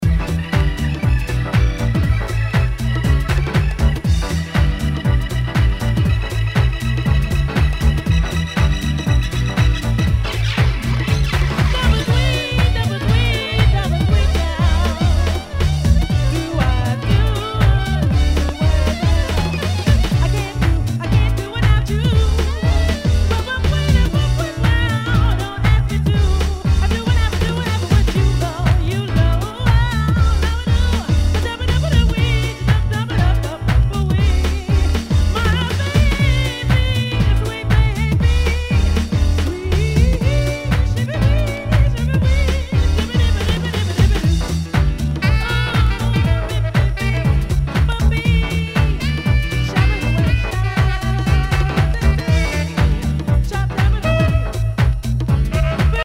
HOUSE/TECHNO/ELECTRO
ナイス！ジャジー・ディープ・ハウス！